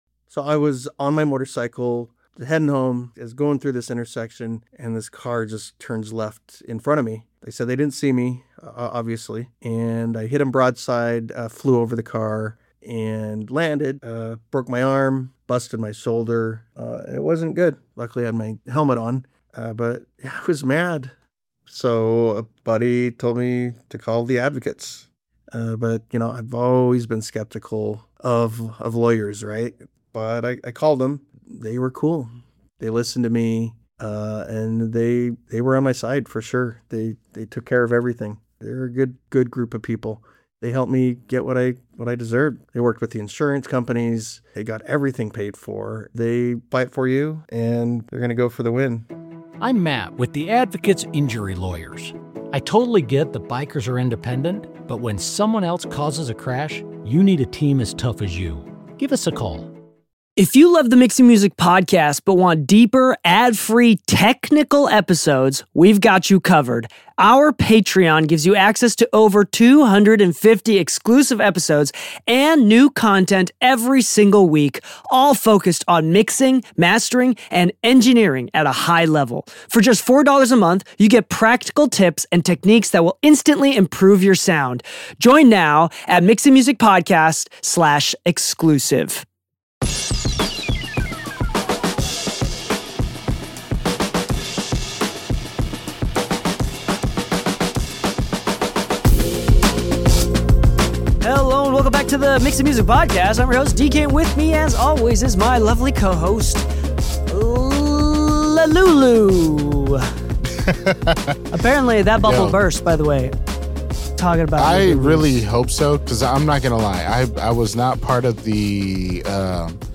Episode 355 is a fast, opinionated end-of-year plugin conversation